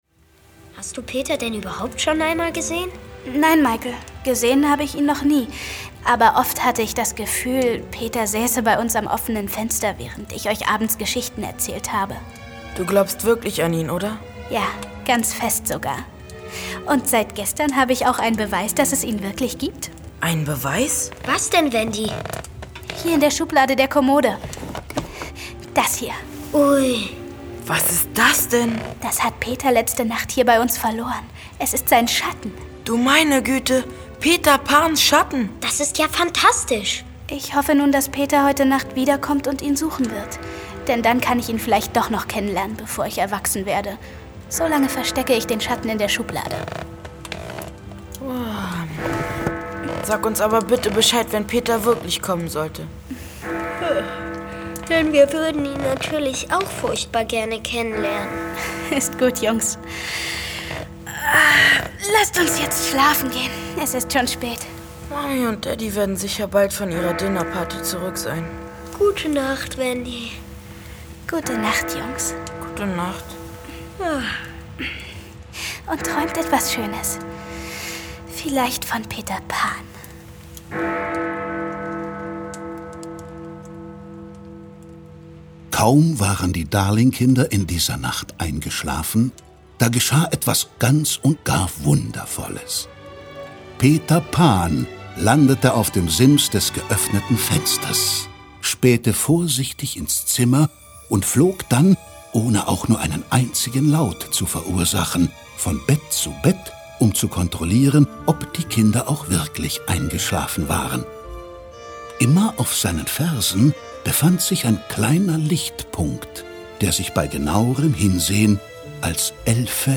Titania Special. Hörspiel. Empfohlen ab 8 Jahren